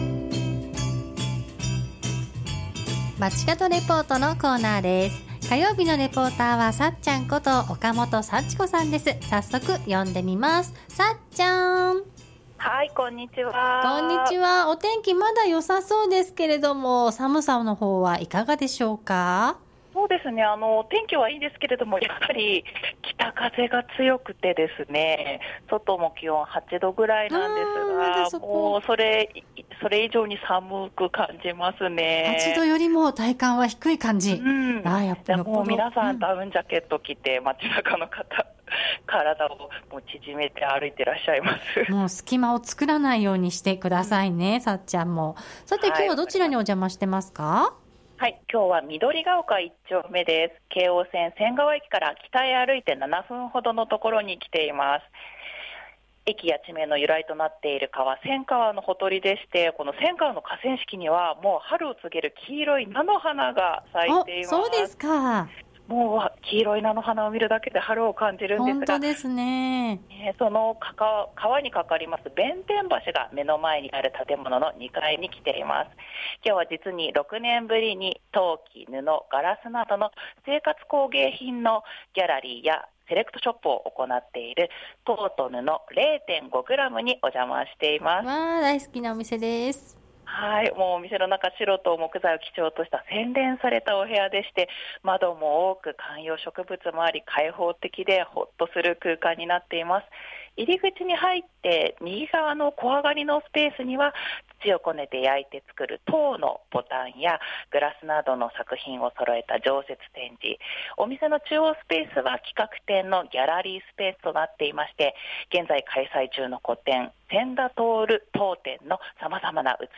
中継は京王線・仙川駅から北へ徒歩7分の場所にある「陶と布 0.5gram」に6年ぶりにお邪魔しました。 仙川のほとりにある建物の2階にある陶器、布、ガラスなどの生活工芸作品のギャラリー＆セレクトショップです。